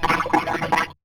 absolutely sickening sloshing sound.
nightslashers-slosh.wav